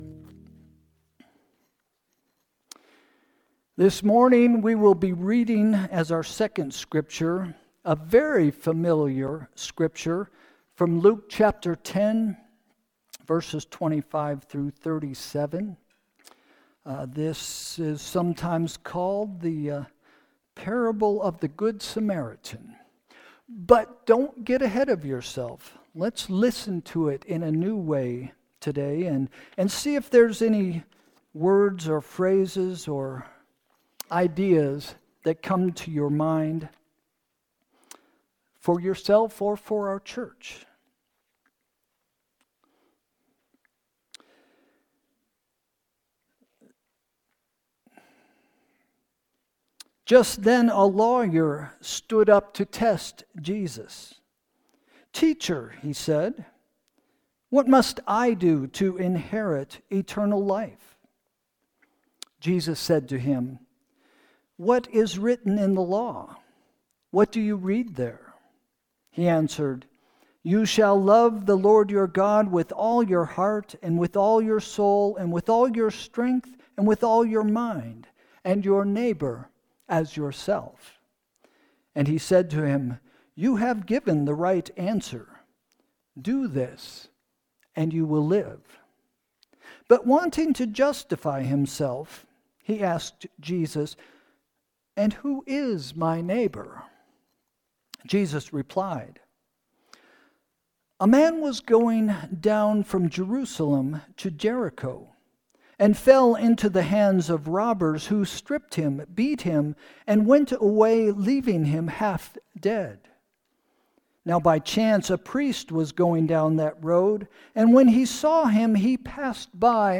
Sermon – February 16, 2025 – “Find A Neighbor” – First Christian Church